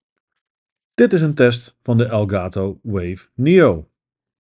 Voor de test hebben we de Wave Neo aangesloten op een MacBook Pro met Garageband om te zien hoe de Wave Neo het doet als we wat opnames gaan maken.
Met stemisolatie
Vergelijken we de opname met degene waar we wel stemisolatie hebben aangezet, dan is het verschil duidelijk te horen en valt vooral het verschil in helderheid op.
Elgato-Wave-Neo-met-stemisolatie.m4a